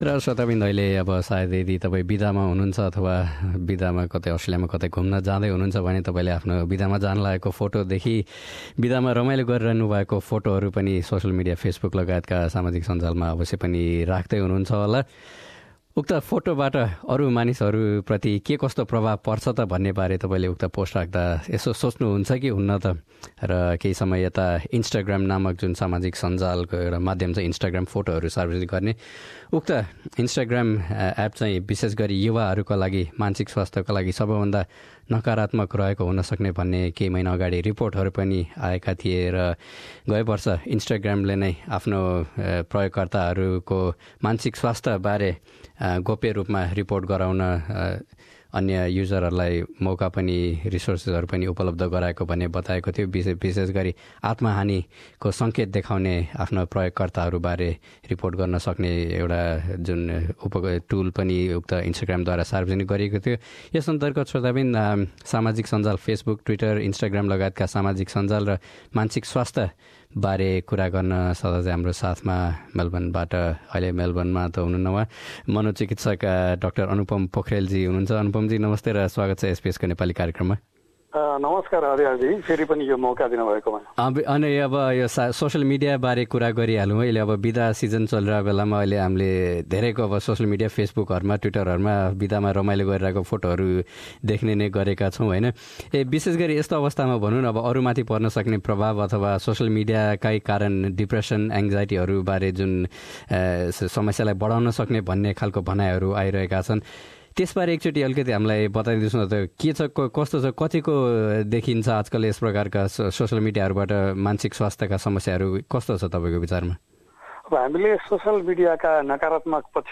कुराकानी।